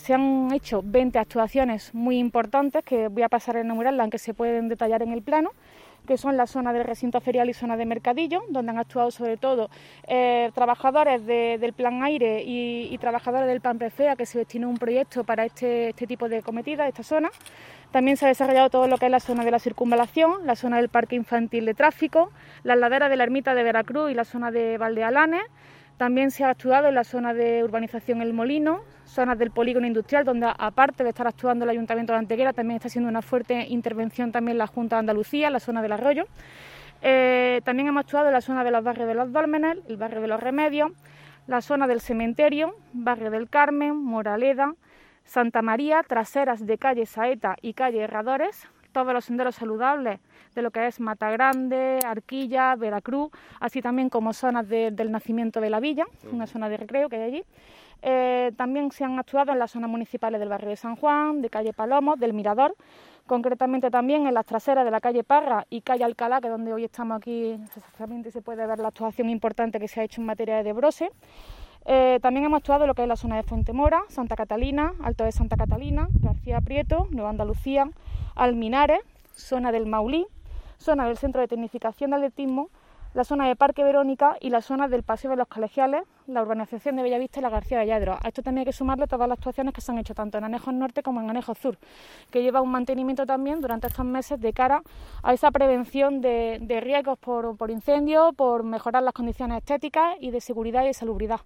El alcalde de Antequera, Manolo Barón, y la teniente de alcalde delegada de Mantenimiento, Teresa Molina, han informado hoy en rueda de prensa sobre el desarrollo del Plan Municipal de Desbroce 2021 que ya se encuentra en fase de mantenimiento tras su acometida desde el pasado mes de marzo.
Cortes de voz